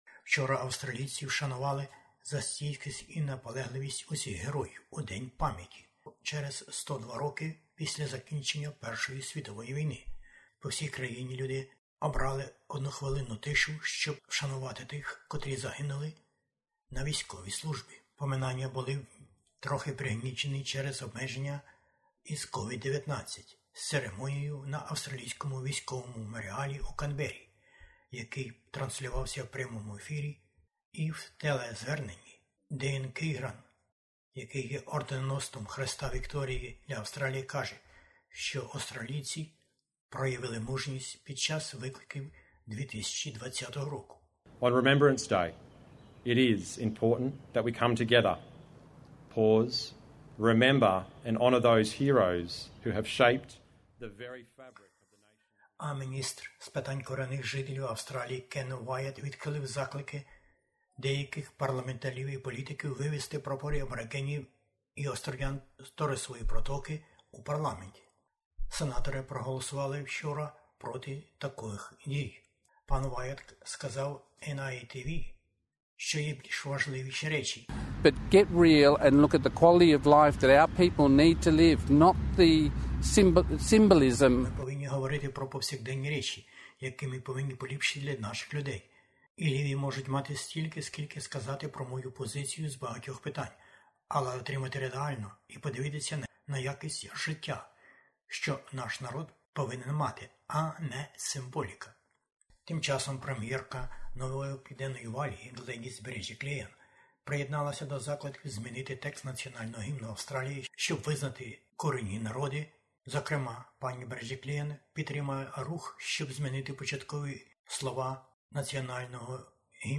SBS НОВИНИ УКРАЇНСЬКОЮ